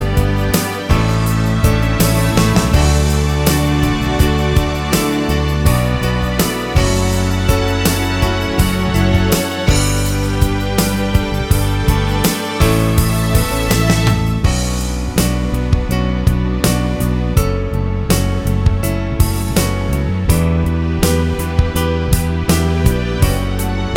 No Electric Or Acoustic Guitars Pop (1990s) 4:01 Buy £1.50